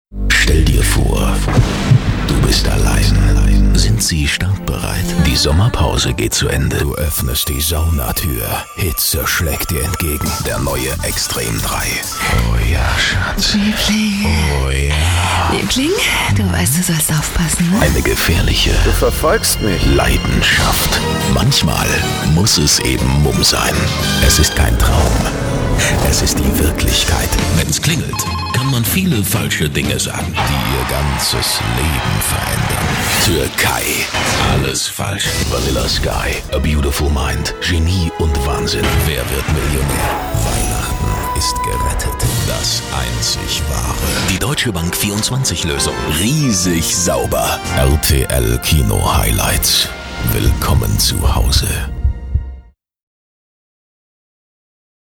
Tiefe, eindringliche Stimme.
Sprechprobe: Werbung (Muttersprache):
german voice over artist